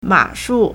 马术 (馬術) mǎshù
ma3shu4.mp3